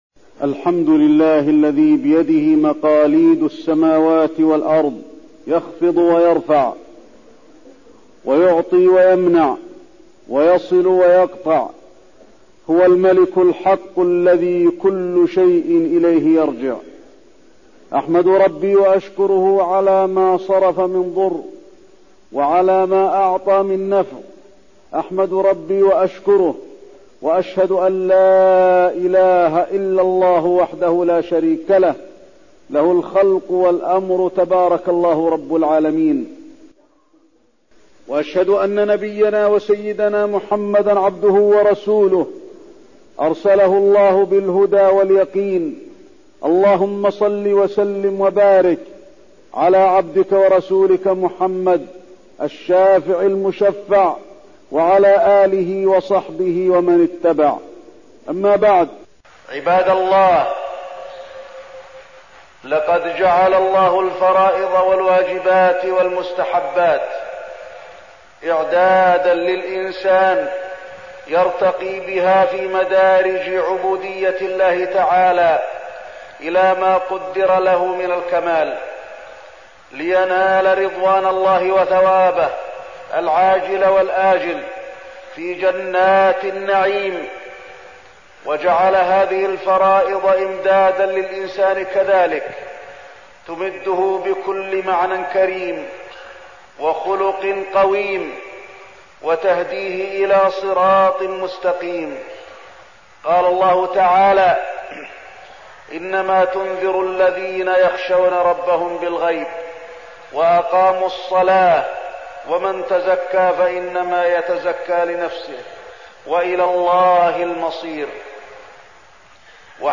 تاريخ النشر ١٦ جمادى الآخرة ١٤١٨ هـ المكان: المسجد النبوي الشيخ: فضيلة الشيخ د. علي بن عبدالرحمن الحذيفي فضيلة الشيخ د. علي بن عبدالرحمن الحذيفي إن الله شديد العقاب وإنه لغفور رحيم